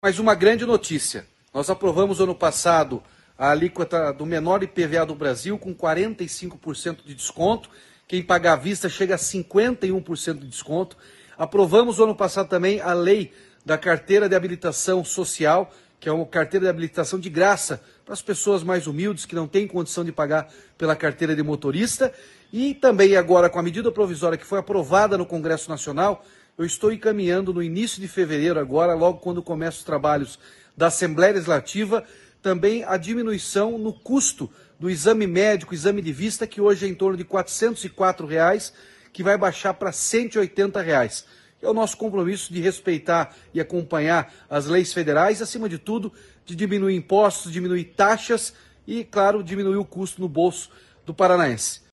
Sonora do governador Ratinho Junior sobre o projeto de lei para reduzir em 55% o custo dos exames da CNH no Paraná